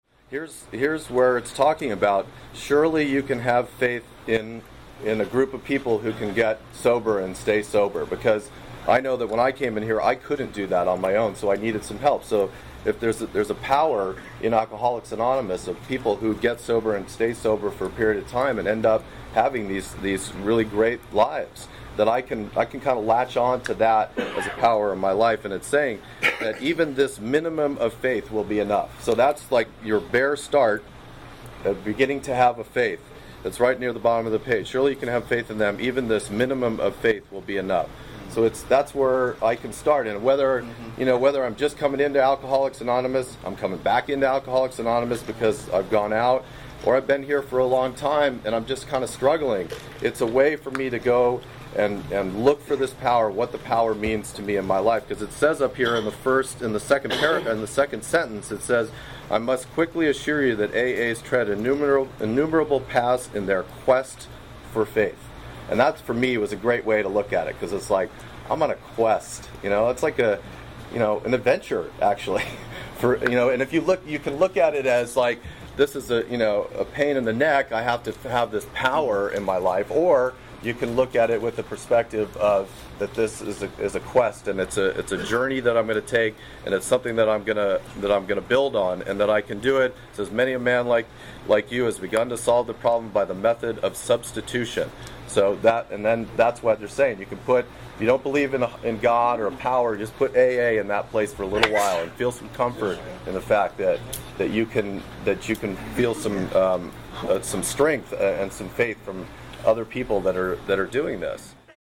Participants share insights on approaching the program gradually, allowing space for growth in faith, and the profound influence of spiritual connection on both recovery and relationships with loved ones.